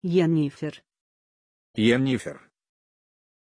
Aussprache von Jennifer
pronunciation-jennifer-ru.mp3